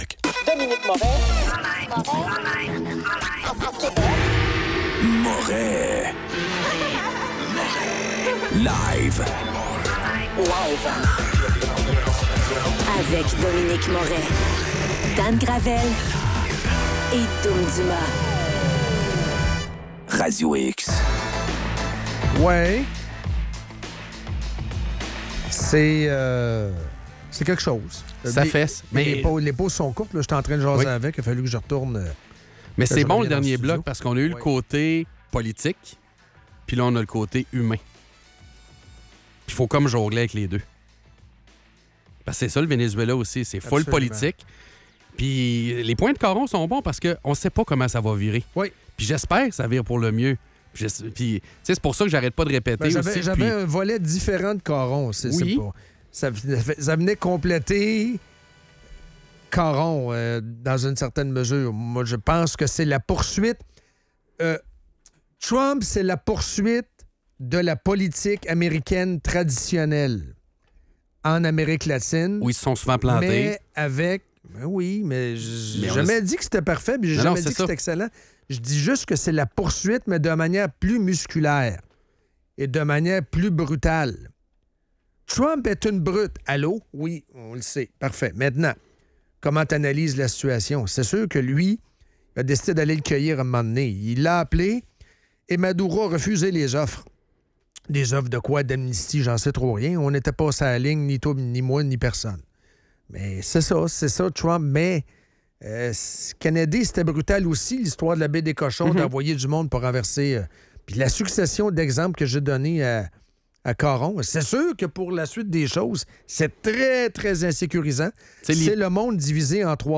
En entrevue, Éric Duhaime.